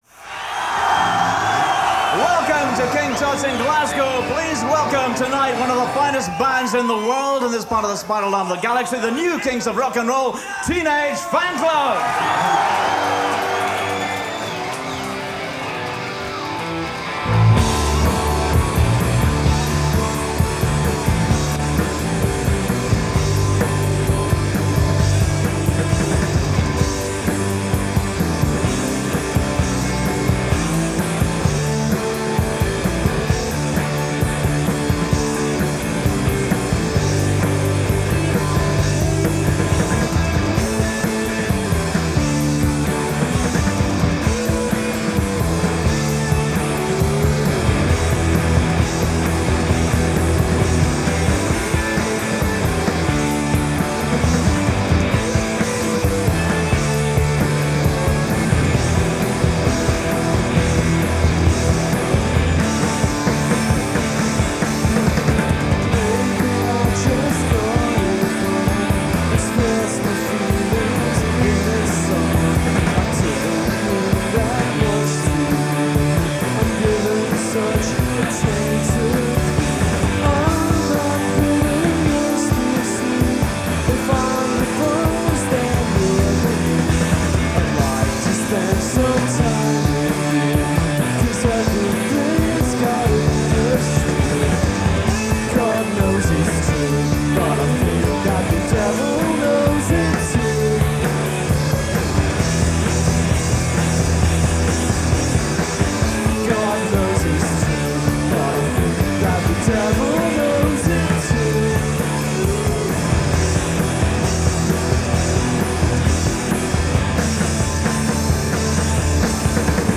magnificently heavy, yet harmony rich.